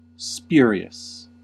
Ääntäminen
IPA : /ˈspjʊə.ɹi.əs/